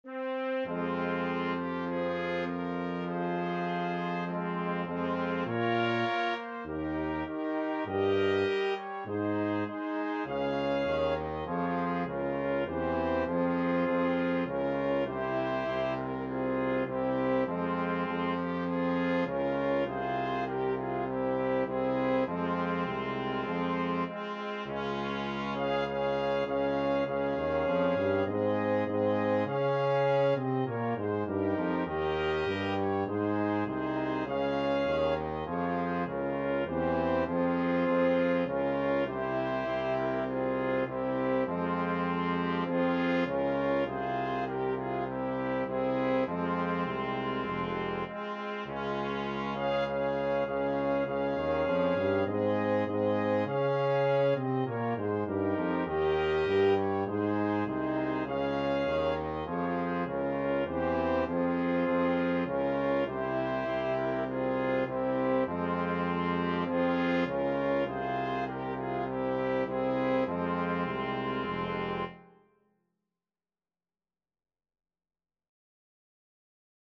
Trumpet 1Trumpet 2French HornTromboneTuba
4/4 (View more 4/4 Music)
Tempo di marcia
Brass Quintet  (View more Easy Brass Quintet Music)